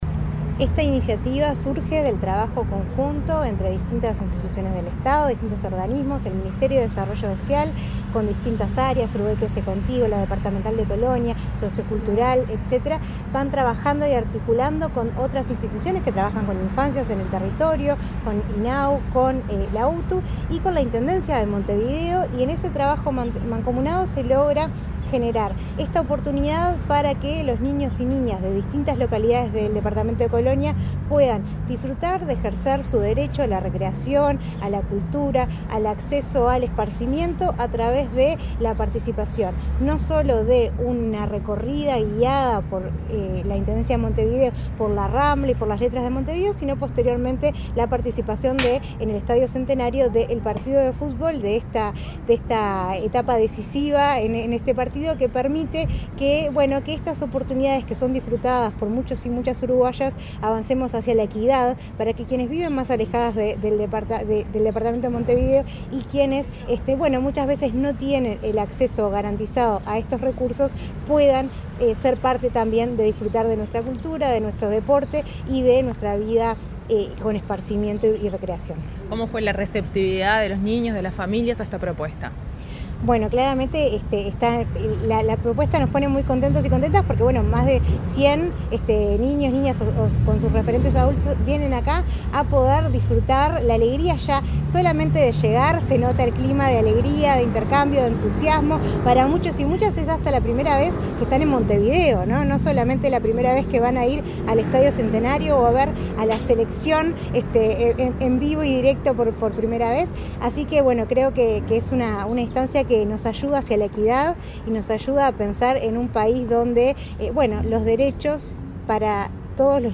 Declaraciones de la gerenta de Uruguay Crece Contigo, Virginia Cadozo